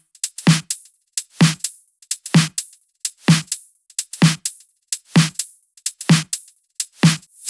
VFH3 128BPM Wobble House Kit
VFH3 128BPM Wobble House Kit 6.wav